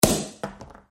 Открыли шампанское, пробка упала